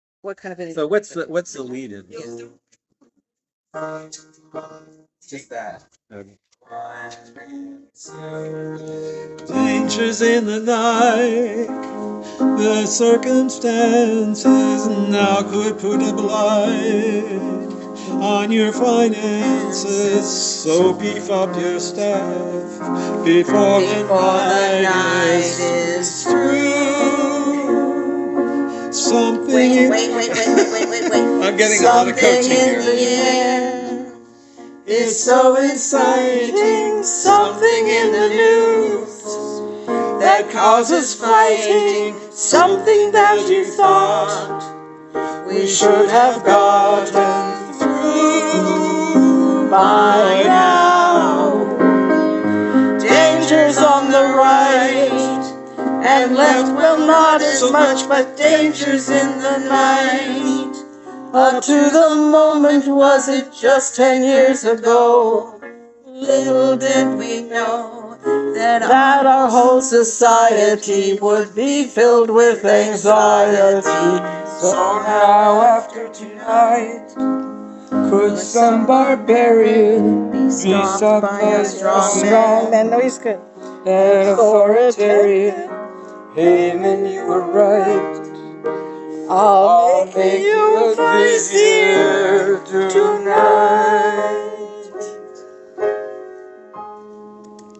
Rough recordings.
Tempos on some things could get faster when we are more familiar.